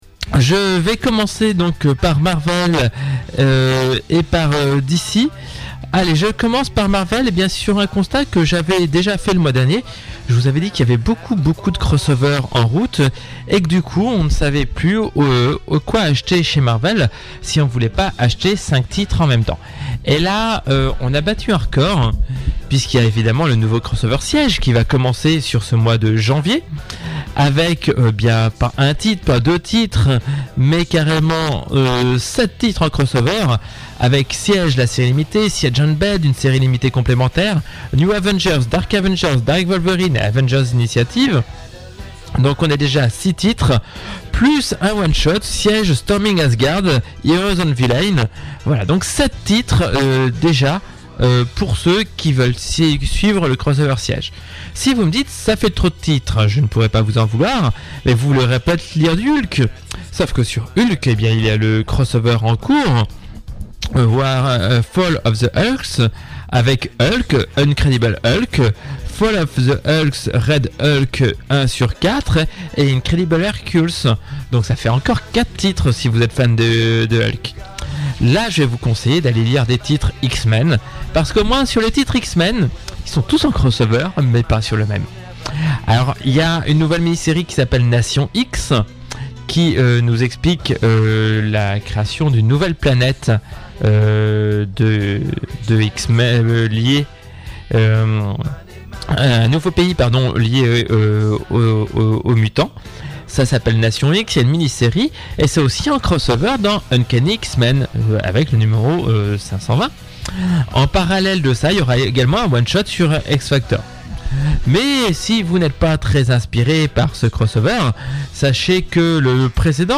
L’émission du jour aura cumulé un nombre record de dysfonctionnements : des micros au téléphone tout à joué contre moi : de quoi me donner la dent dure pour cette nouvelle revue de dépress’ Comics.